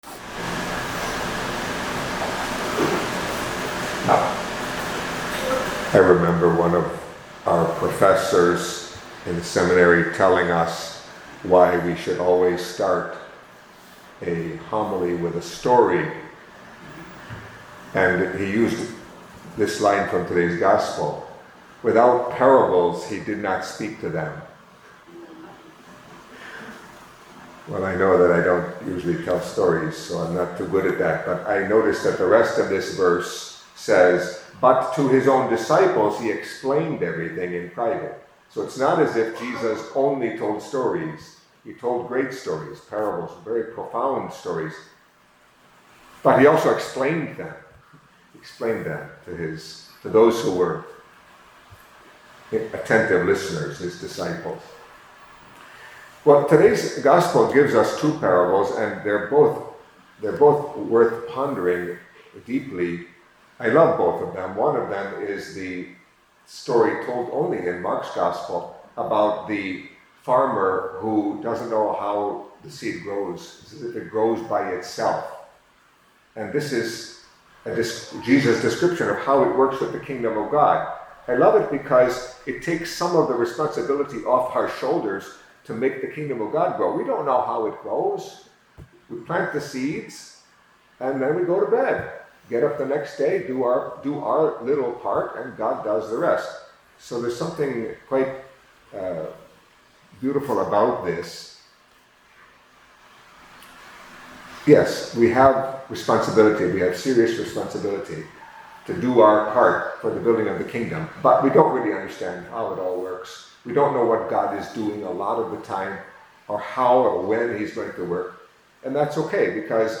Catholic Mass homily for Friday of the Third Week in Ordinary Time